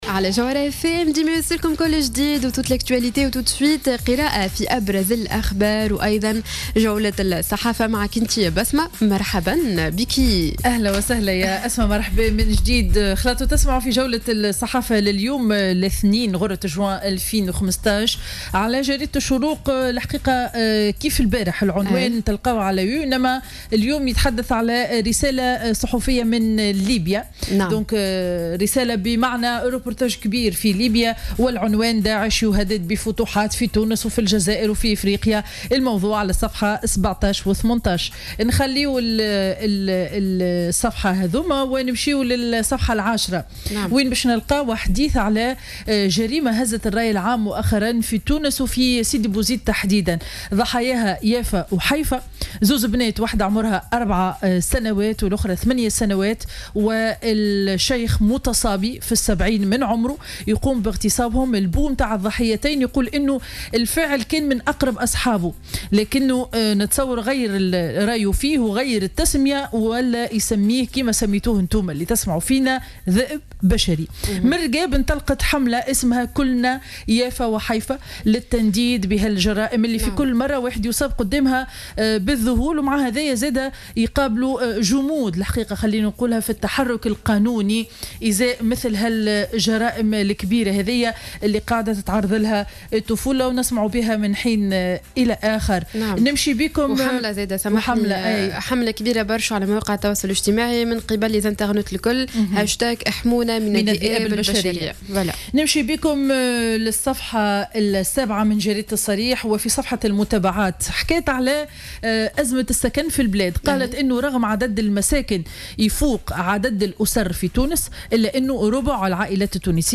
Revue de presse du 01 Juin 2015